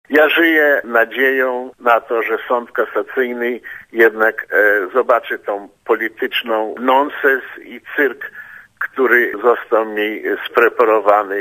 Żyję nadzieją na to, że sąd kasacyjny zobaczy ten polityczny nonsens i cyrk, który został mi spreparowany - powiedział Lew Rywin w rozmowie z reporterem Radia Zet.
Mówi Lew Rywin
rywin-rozmowa.mp3